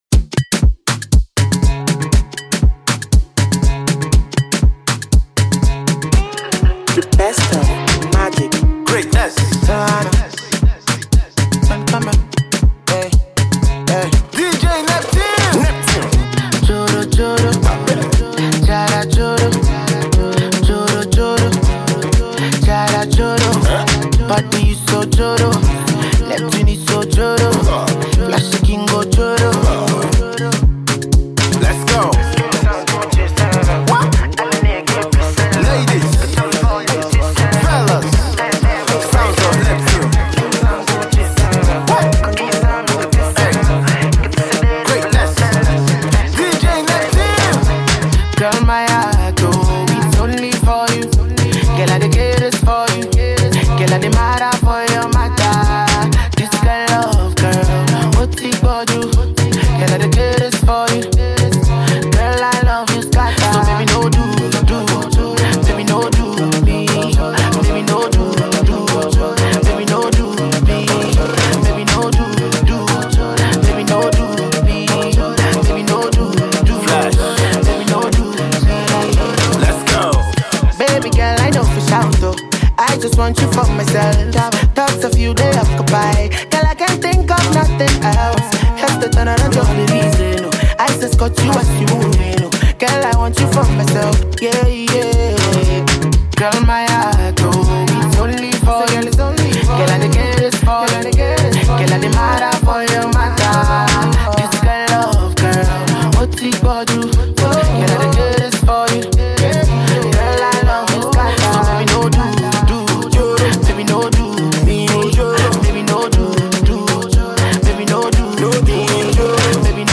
summer record